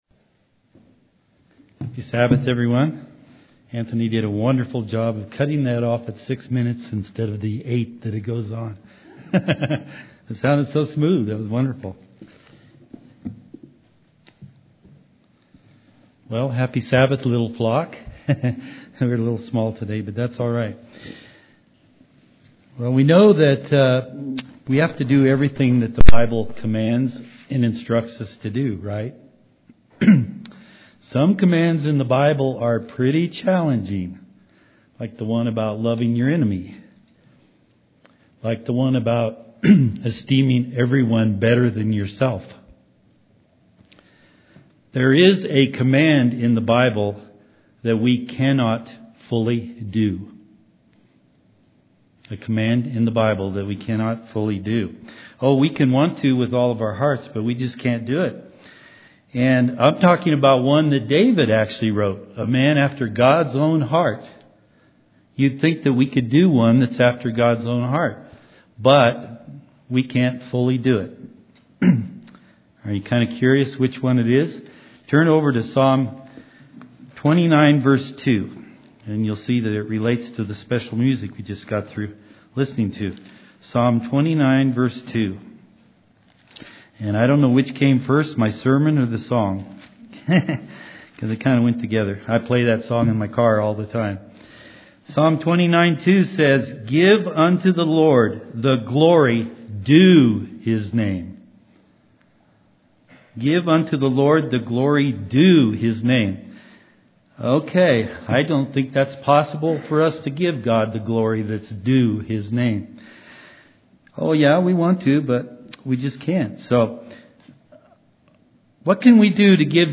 Sermons
Given in Colorado Springs, CO